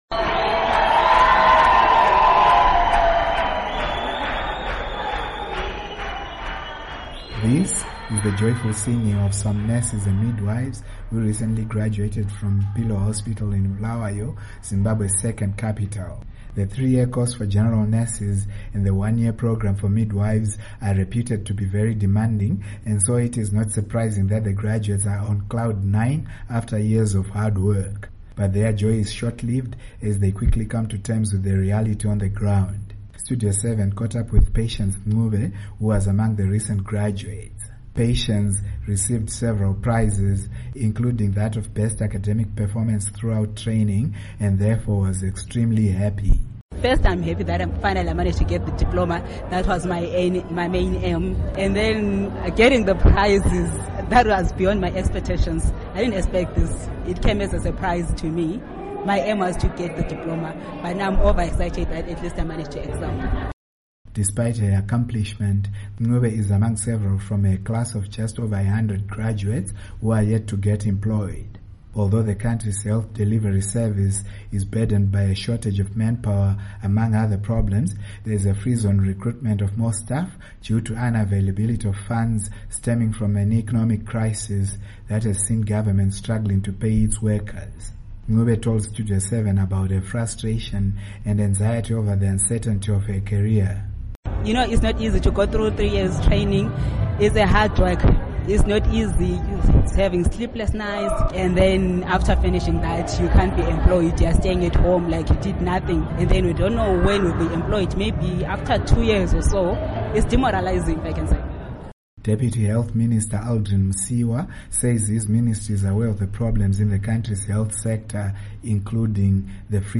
Report on Nurses, Midwives